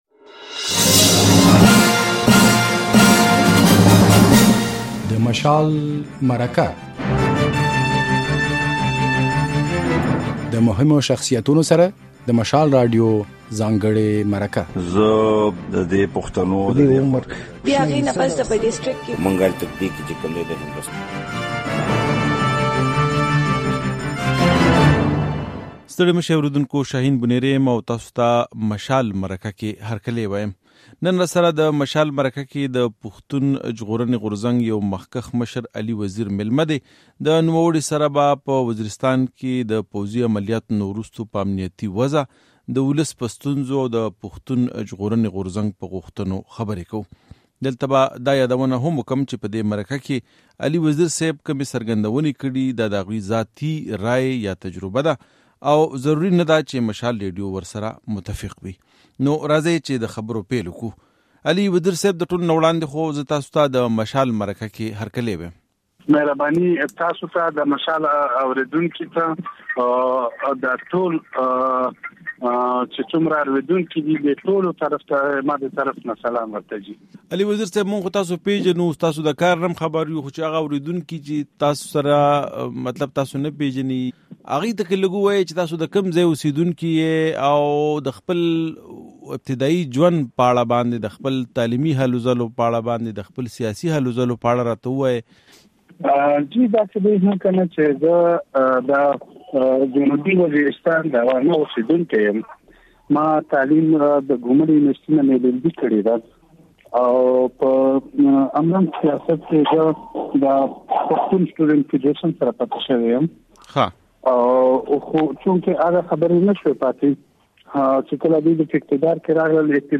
د مشال مرکې پاڼې ته ښه راغلاست. دا اونۍ د پښتون ژغورنې غورځنګ له یو مشر علي وزیر سره غږېدلي یو.